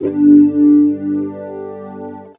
C GTR 2.wav